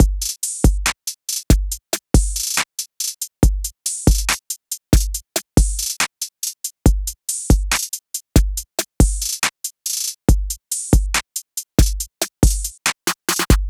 SOUTHSIDE_beat_loop_herb_full_01_140.wav